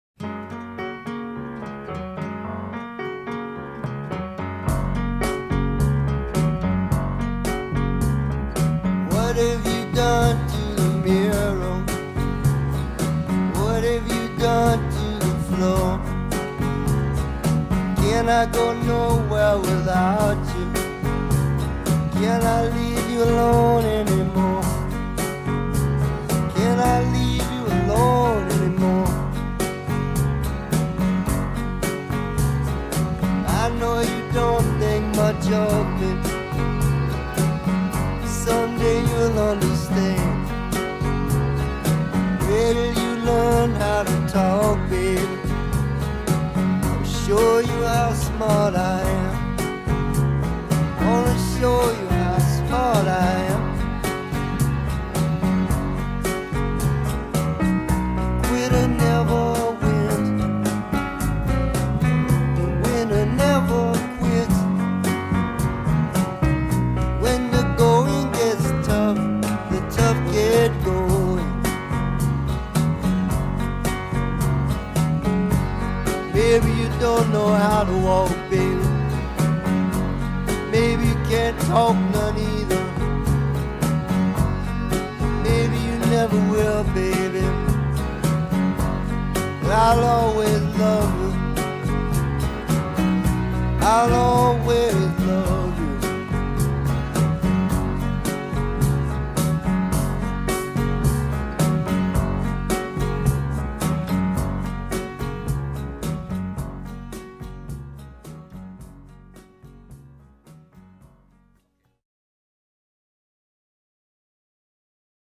is a rolling lullaby
It’s charming and cute